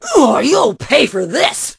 pam_hurt_vo_01.ogg